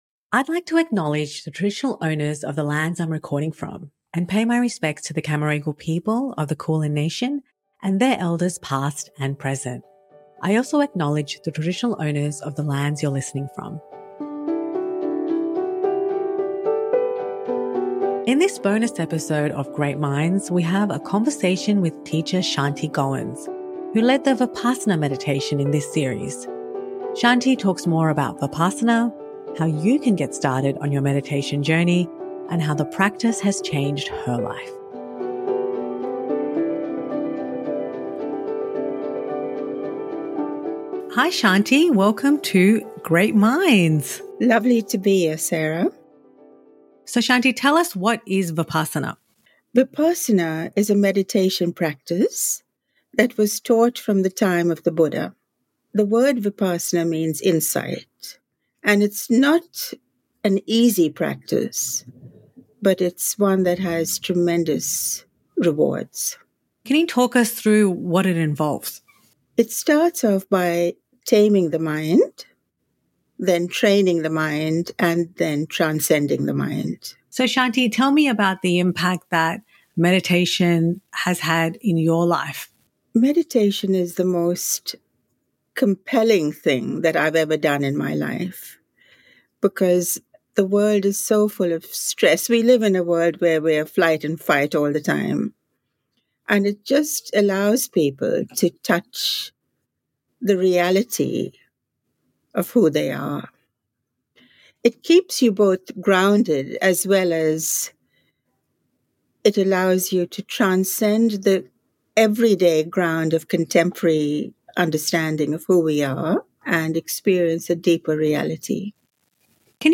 Bonus interview